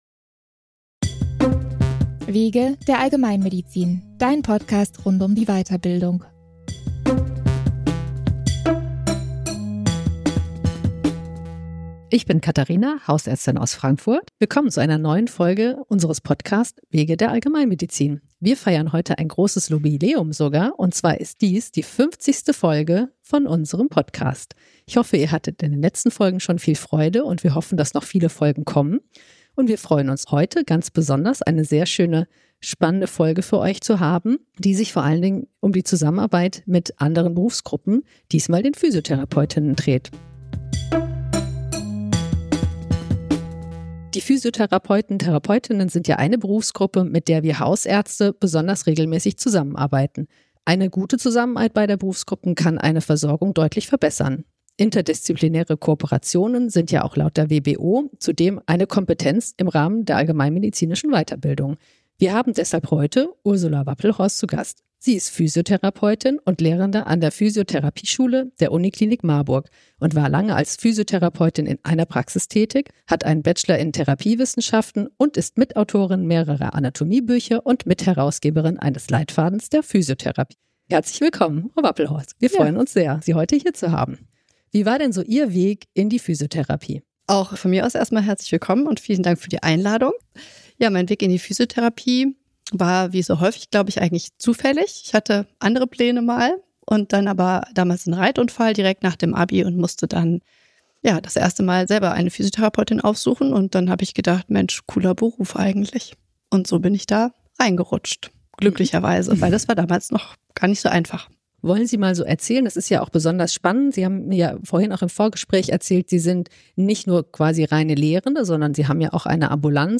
Im Gespräch erfahren wir einiges über das Berufsbild der Physiotherapie sowie mögliche Verbesserungen in der Zusammenarbeit zwischen Physiotherapie und Allgemeinmedizin.